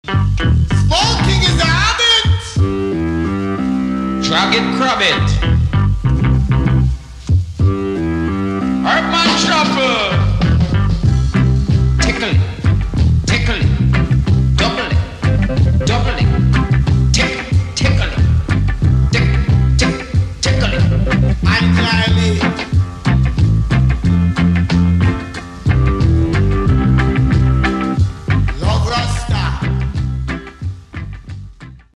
kytara
piano
varhany
bicí